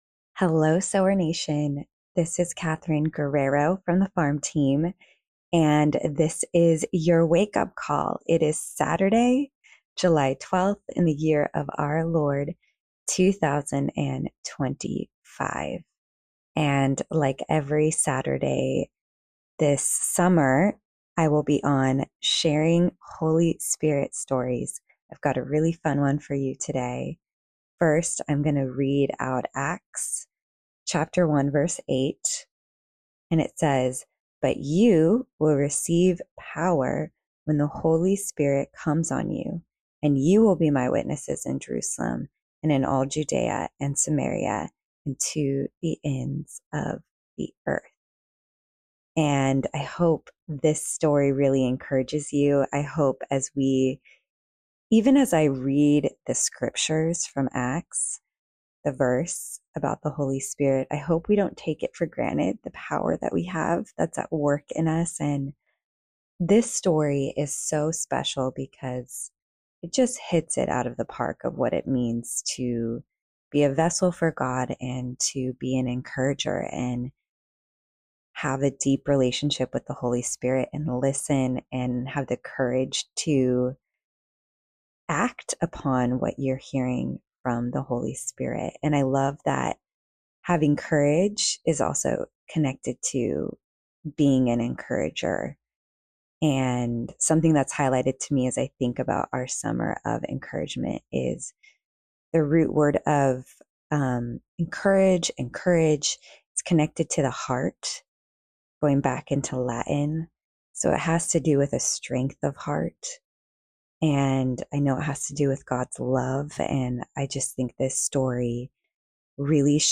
I know you will be deeply encouraged by this story because it's all about the Holy Spirit coming in to make sure one of his kids knows God sees and knows him deeply. Below, you will read the story in her own words, and, if you listen, you will hear it in her own voice.